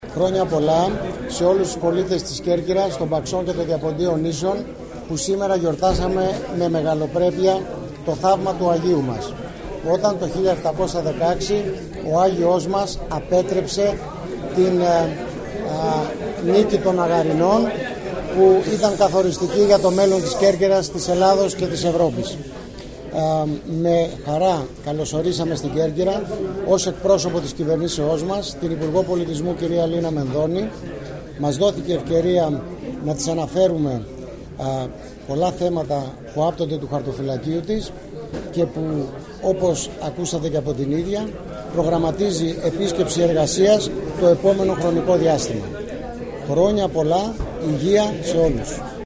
Κέρκυρα: Δηλώσεις πολιτικών και αυτοδιοικητικών (audio)